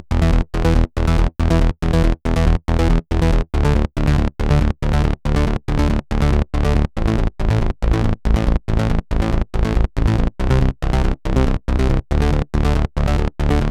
VTS1 Selection Kit Bassline